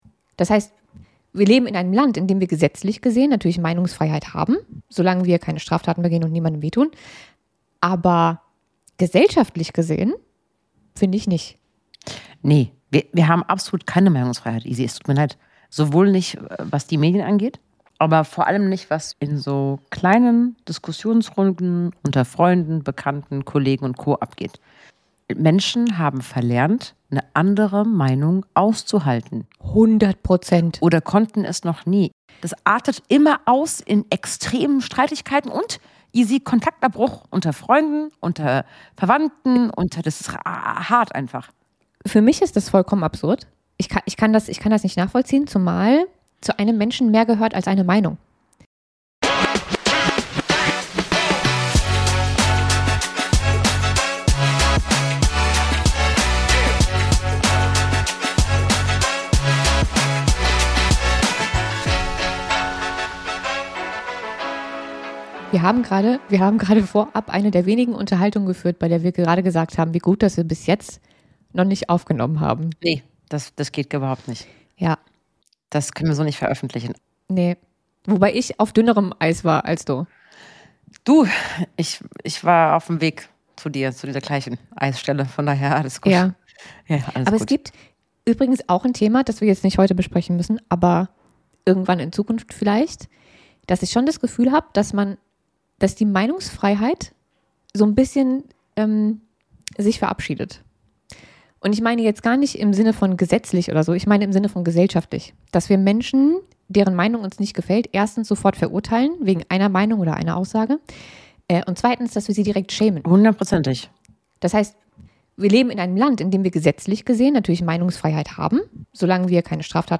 Ein Gespräch über Tabus, innere Freiheit und den Mut, Dinge nicht nur politisch korrekt, sondern ehrlich zu betrachten. Außerdem: • warum wir Meinung mit Moral verwechseln • wie Social Media Weiblichkeit neu definiert und verstellt • und wieso Reue kein weibliches Alleinstellungsmerkmal sein sollte Eine Folge über Schubladen, Standards und die Frage, ob Freiheit heute wirklich noch frei ist.